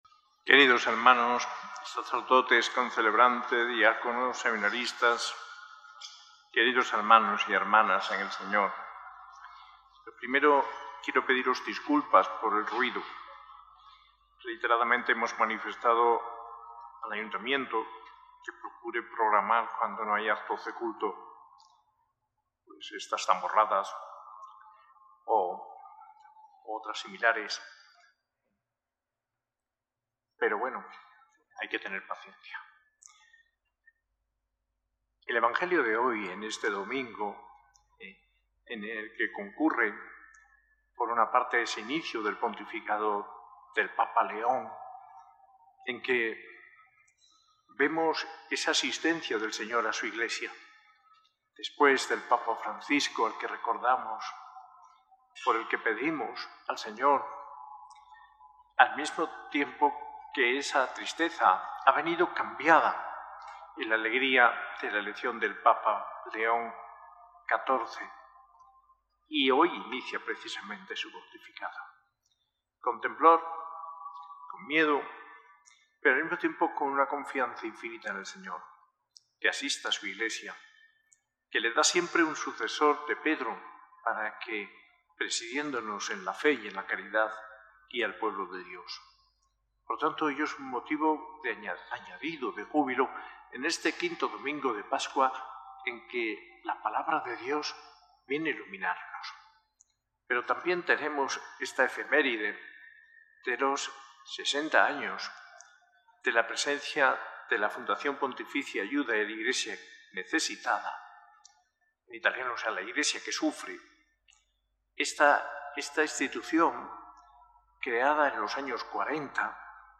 Homilía del arzobispo de Granada, Mons. José María Gil Tamayo, en la Eucaristía del V Domingo del Tiempo Pascual, el 18 de mayo de 2025, en la S.A.I Catedral.